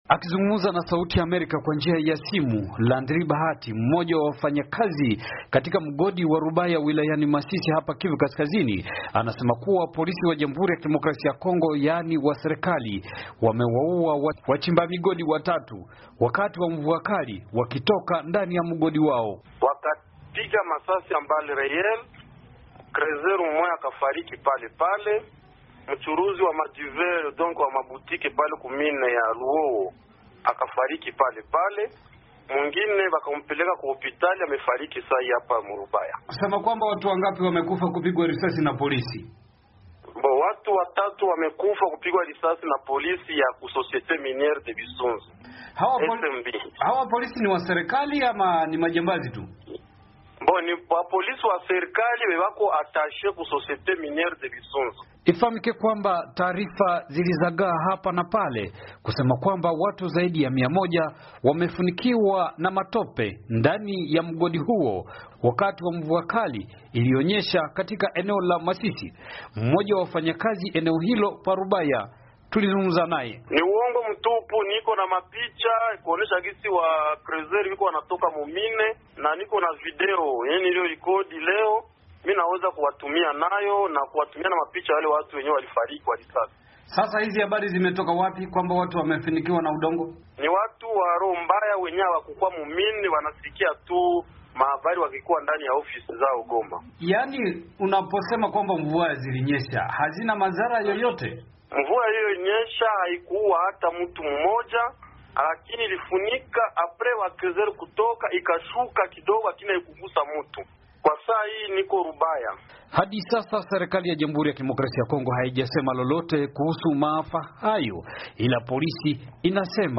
Ripoti